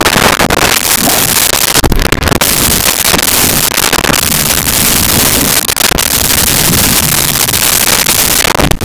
Bear Growl 02
Bear Growl 02.wav